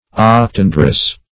Search Result for " octandrous" : The Collaborative International Dictionary of English v.0.48: octandrian \oc*tan"dri*an\, octandrous \oc*tan"drous\, a. (Bot.) Of or pertaining to the Octandria; having eight distinct stamens.
octandrous.mp3